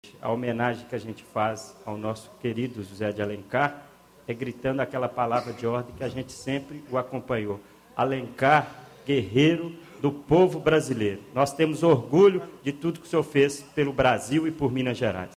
Reunião de Plenário - Pronunciamento sobre o ex-vice-presidente da república, José Alencar